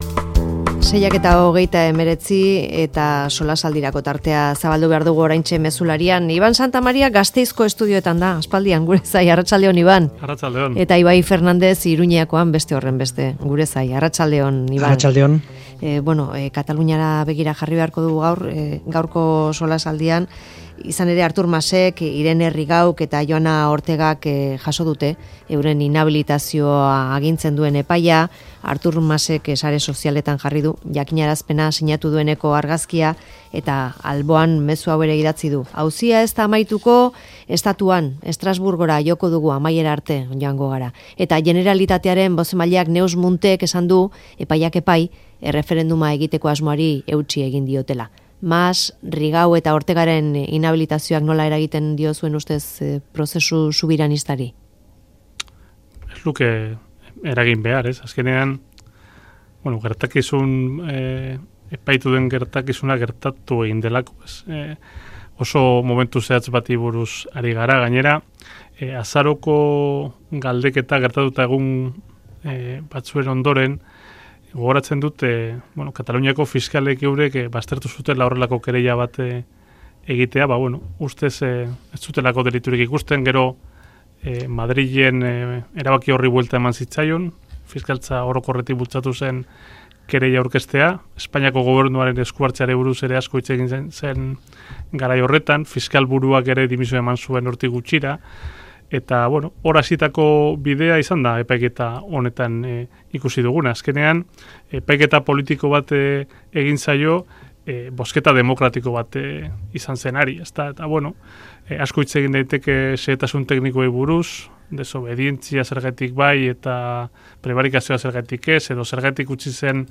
Mezularia|Solasaldia